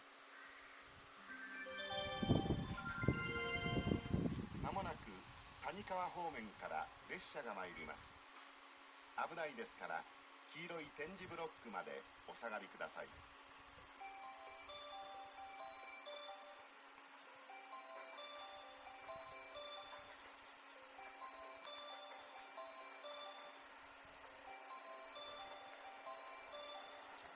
この駅では接近放送が設置されています。
接近放送普通　加古川行き接近放送です。